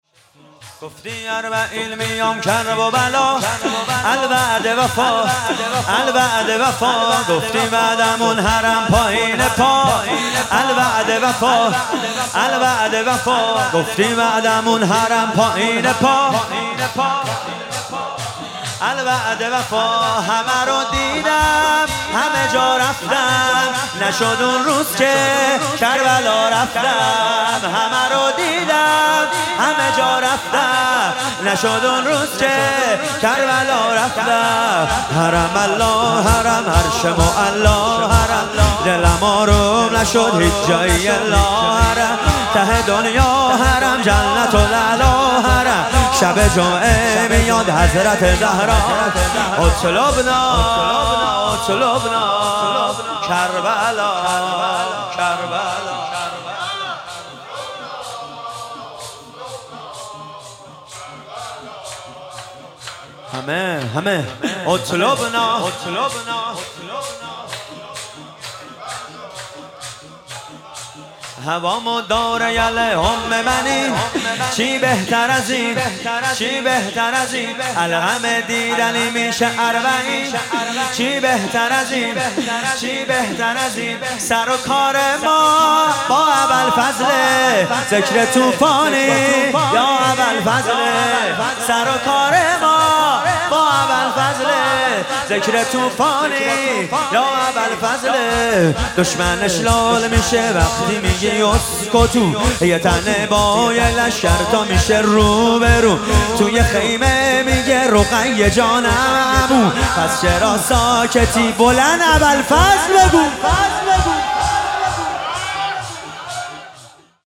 محرم1401 - شب پنجم - شور - گفتی اربعین میام کربلا